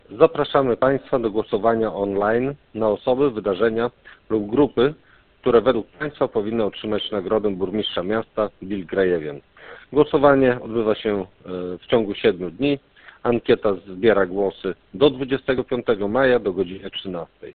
Do głosowania online na osoby, wydarzenia lub grupy, które według słuchaczy powinny otrzymać nagrodę Burmistrza Miasta „Wilk Grajewian” zachęca Maciej Bednarko, zastępca burmistrza miasta Grajewa.